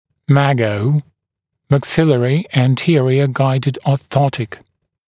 [mæk’sɪlərɪ æn’tɪərɪə ‘gaɪdɪd ɔː’θɔtɪk]